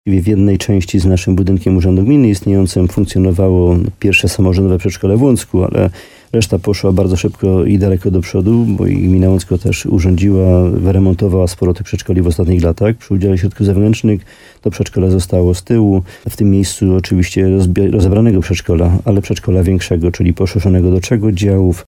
Jak wyjaśnia wójt Jan Dziedzina, placówka nie była już tak nowoczesna i wymagała przebudowy.